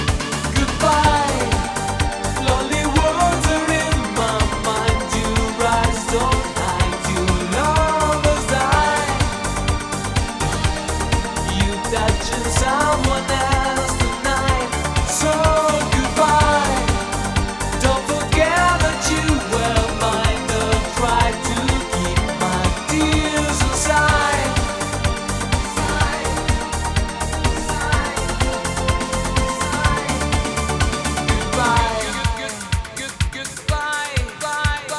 Жанр: Танцевальные / Поп